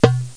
DURBUKA4.mp3